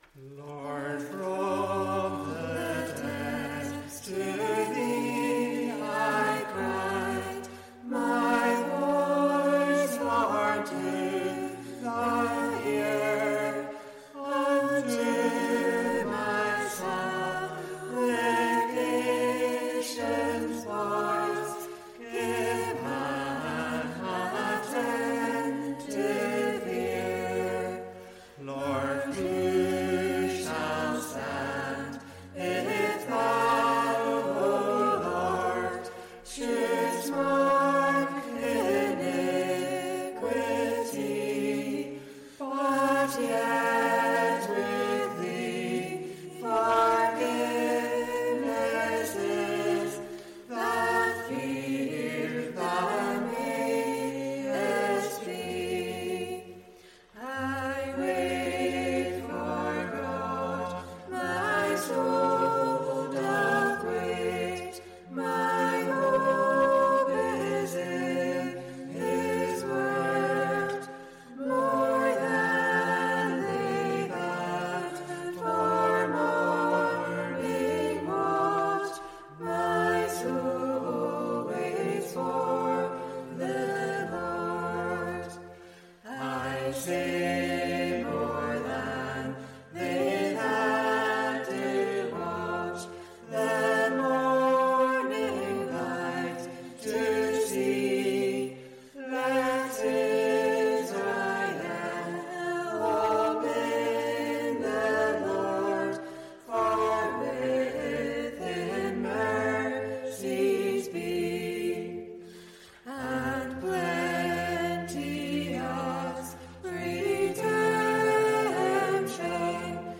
Congregational Psalm Singing
Recorded during the first lockdown when church services went on-line, with five of us singing live at a microphone built for one.